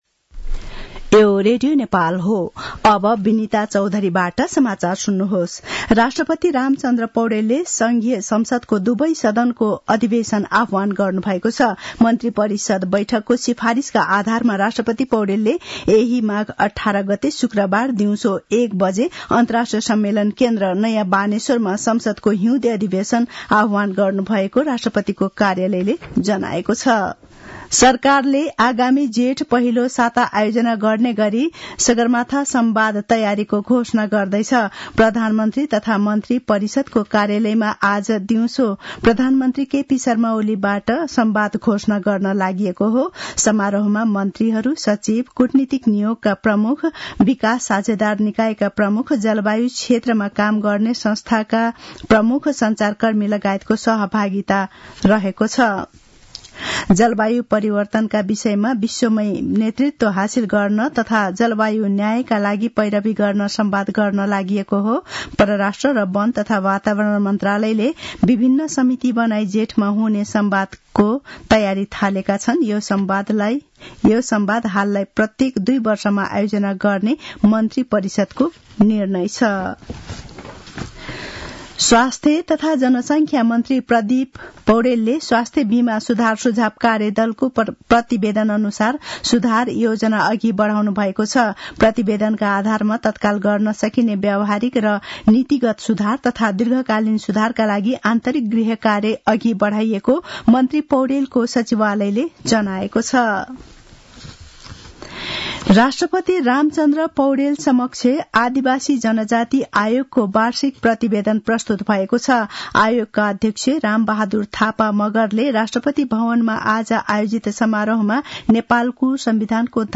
दिउँसो १ बजेको नेपाली समाचार : ९ माघ , २०८१